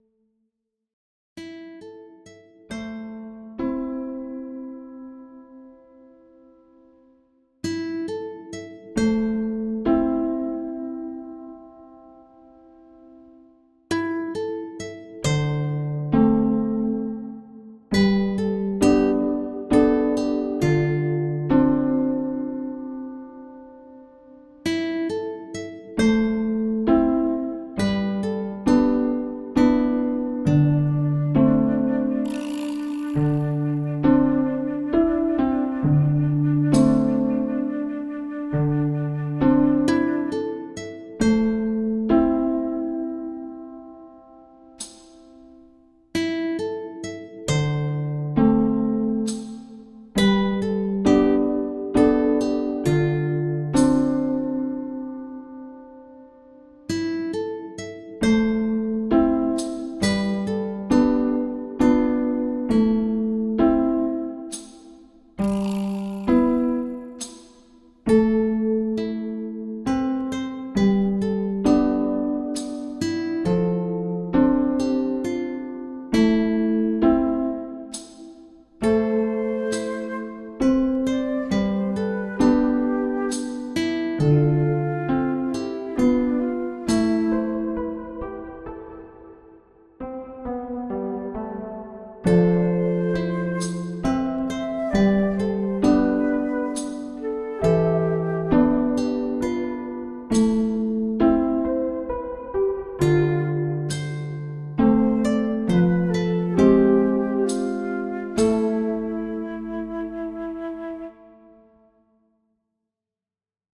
Tavern (sad)_001.ogg